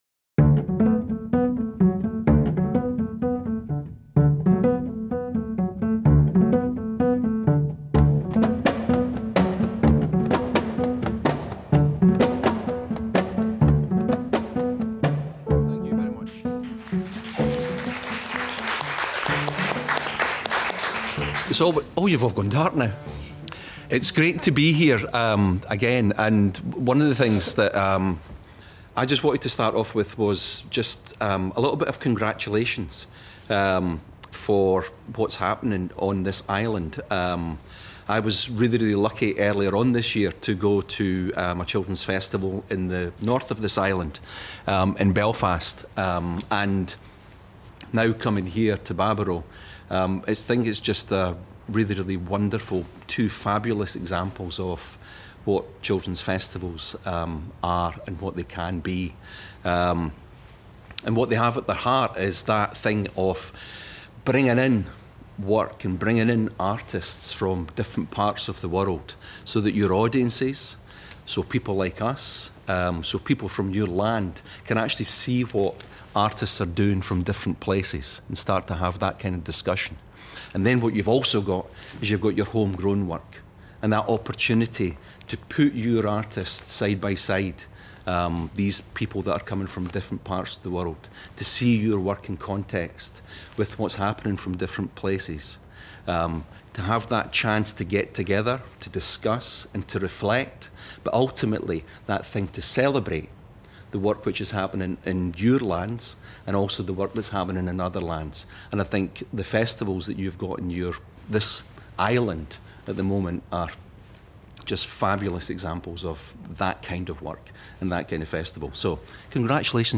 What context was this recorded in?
Recorded on October 17, 2023 during Baboró’s annual festival.